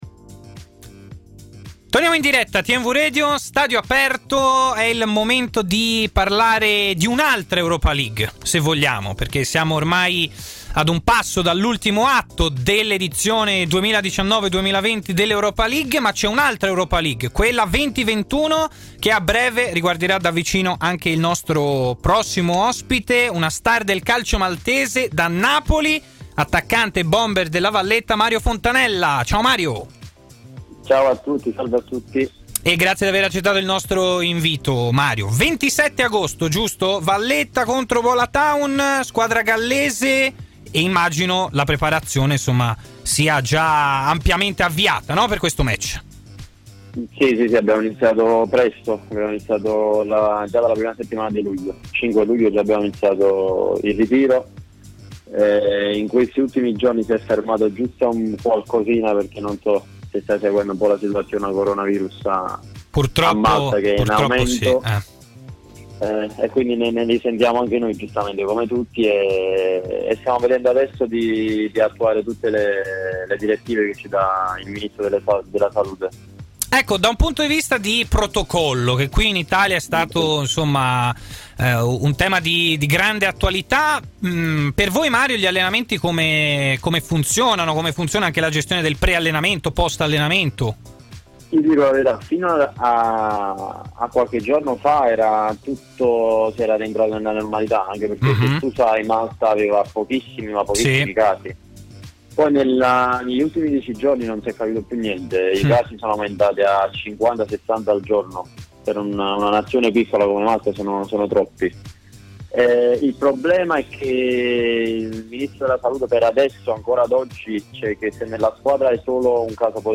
Stadio Aperto, trasmissione di TMW Radio